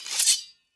Sword Unsheath 1.ogg